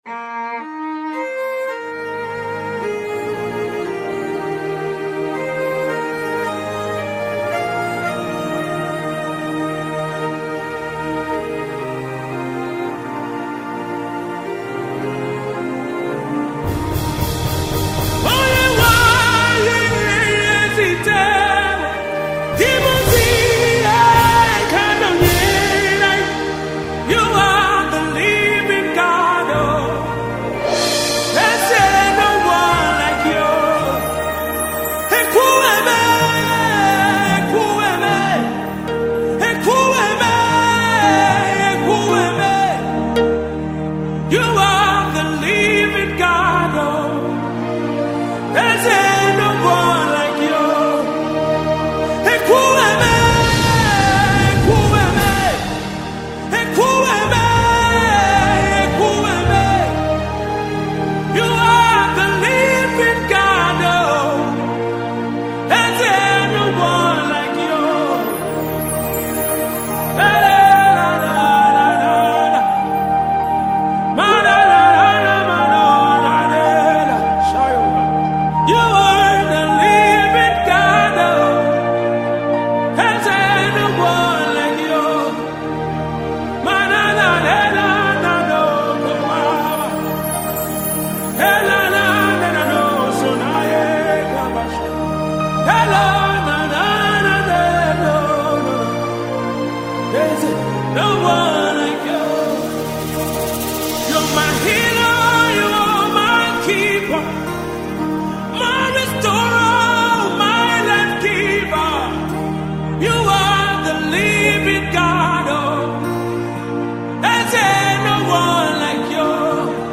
Gospel 2017